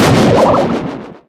tick_atk_split_02.ogg